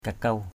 /ka-kau/ (cv.) kakau kk~@ (d.) móng, vuốt = ongle, griffe. nail, claw. kakuw tangin kk~| tz{N móng tay. ongle du doigt de la main. fingernail. kakuw caguw kk~| cg~| vuốt...
kakuw.mp3